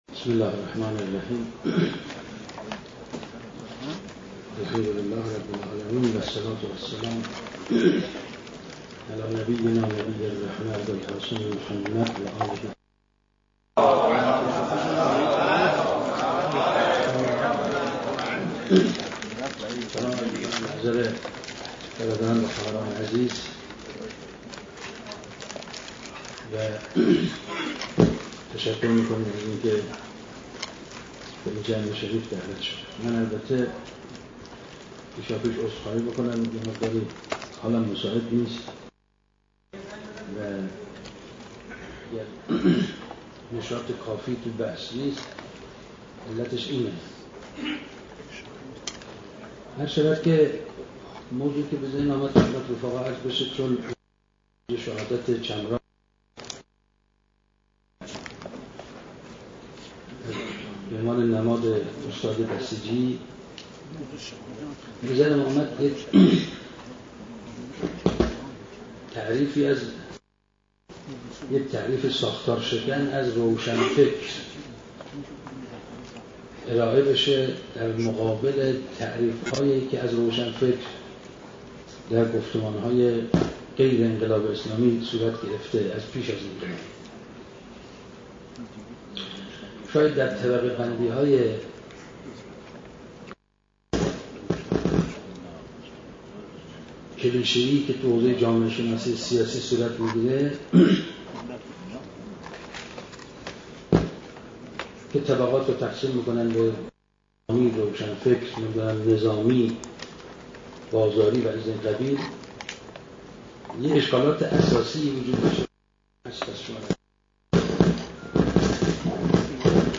بیست و یکمین مقاله شفاهی رحیم‌پور ازغدی؛